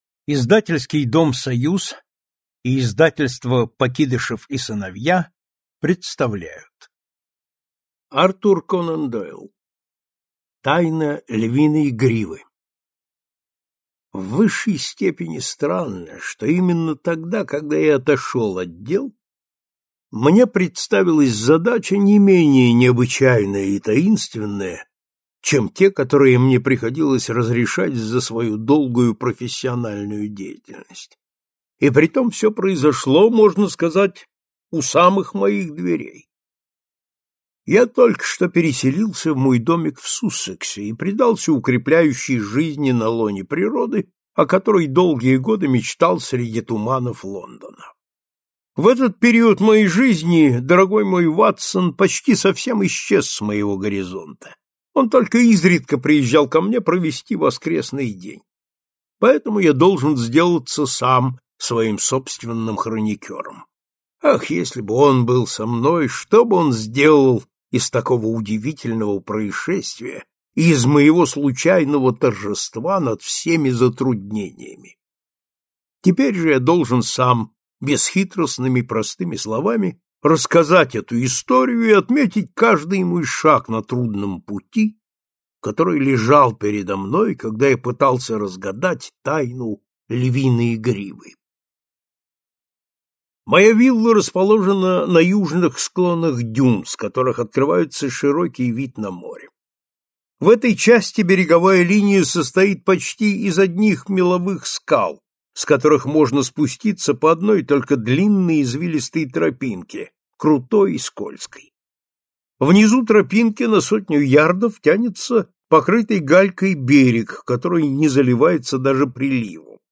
Аудиокнига Тайна львиной гривы | Библиотека аудиокниг